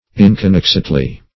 Search Result for " inconnexedly" : The Collaborative International Dictionary of English v.0.48: Inconnexedly \In`con*nex"ed*ly\, adv.